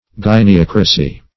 Gyneocracy \Gyn"e*oc`ra*cy\ (j[i^]n`[-e]*[o^]k"r[.a]*s[y^]), n.
gyneocracy.mp3